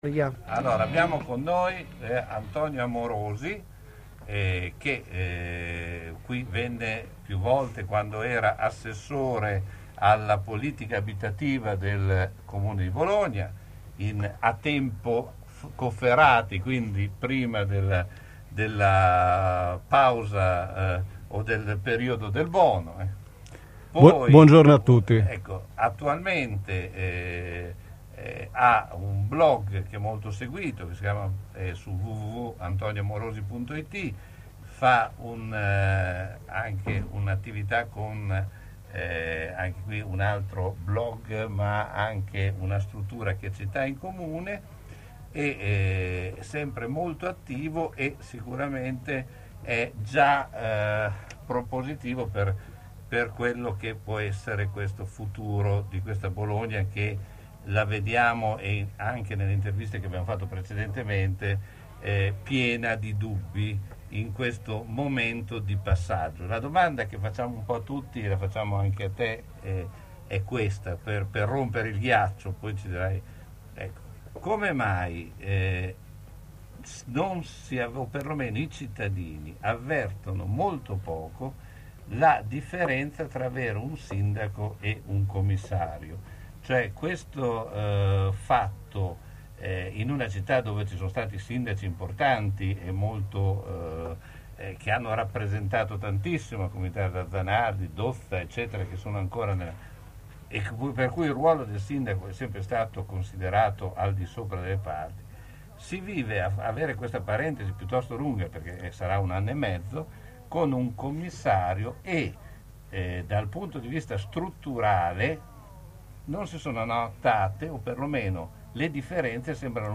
Alle 15.00 di oggi il blog è ospite a Radio San Luchino, storica radio di Bologna